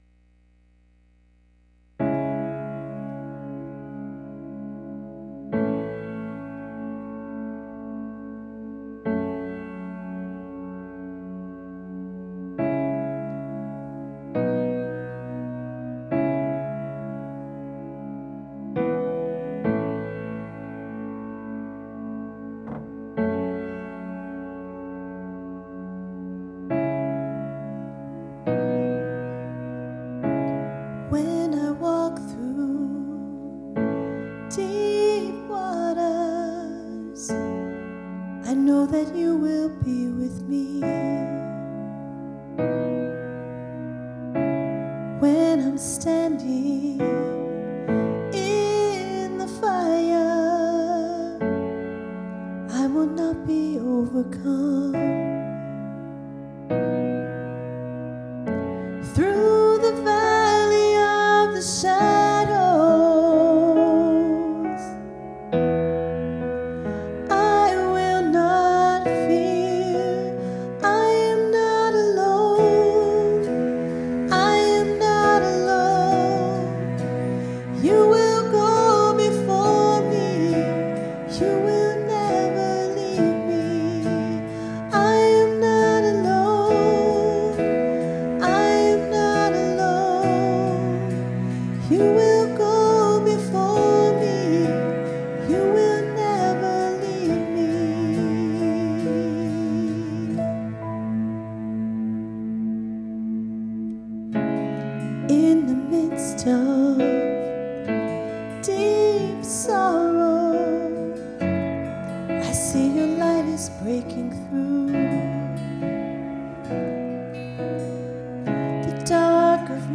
Sunday Morning Music
Solo - I Am Not Alone